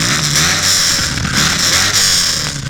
SFX REV IT01.wav